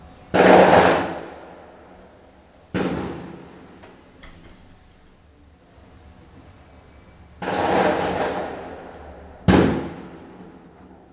Petarados
Bolivie, Santa Cruz, le 2/09/14, « petarados », les pétards, sport national bolivien.